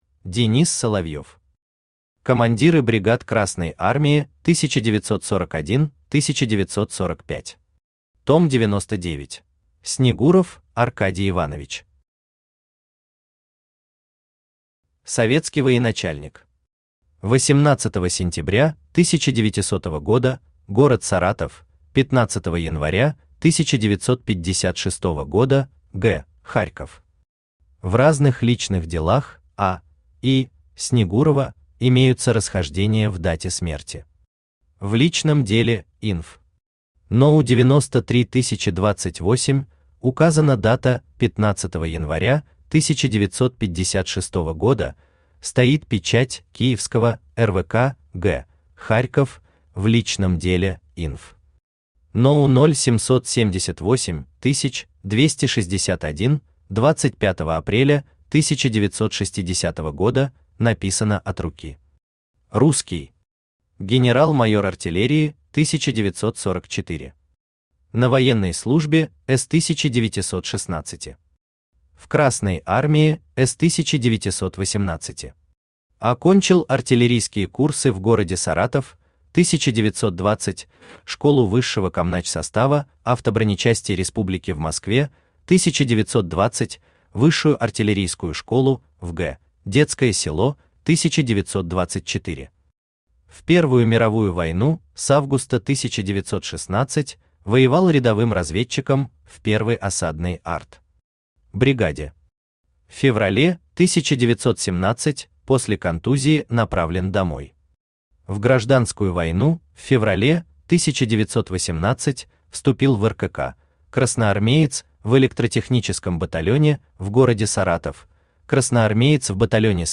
Том 99 Автор Денис Соловьев Читает аудиокнигу Авточтец ЛитРес.